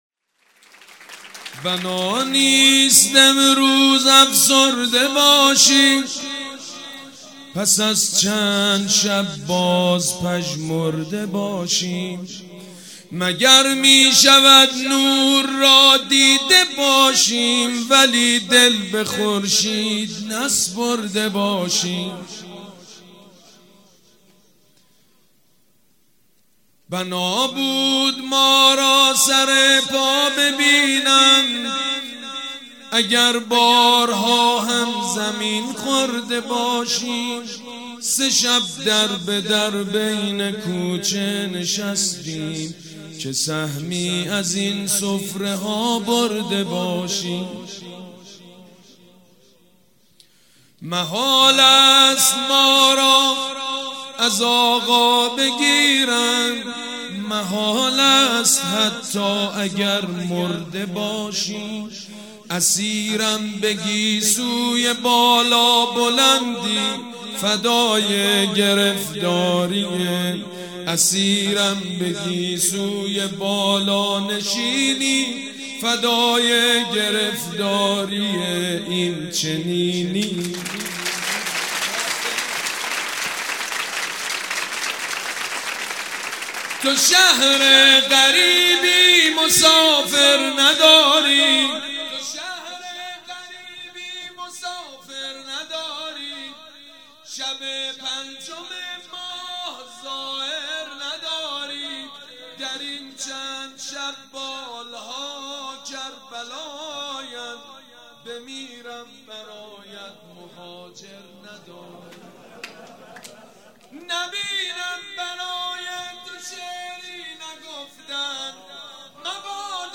ویژه‌نامه ولادت امام زین العابدین حضرت سجاد علیه السلام شامل زندگی‌نامه، تصاویر مزار متبرکه، اشعار ، احادیث و گلچین مداحی، منتشر می‌شود.